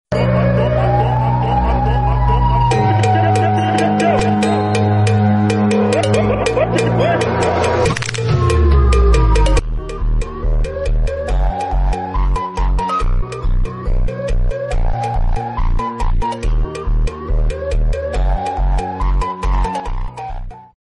Siren head X Scania